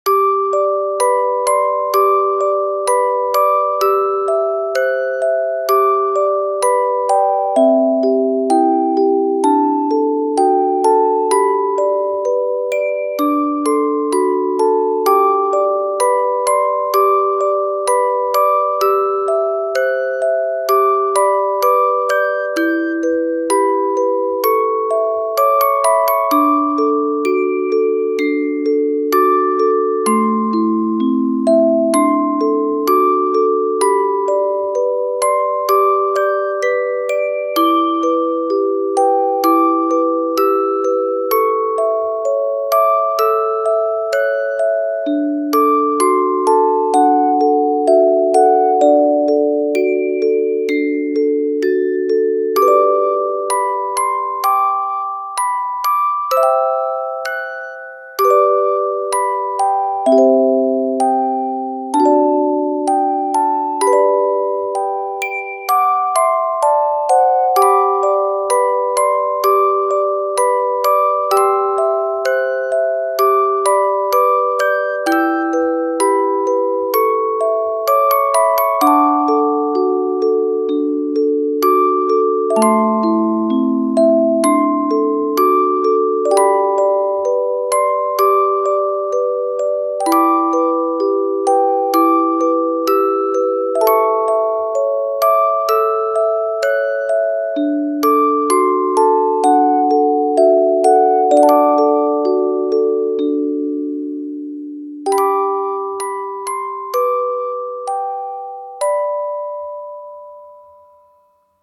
カテゴリ：オルゴール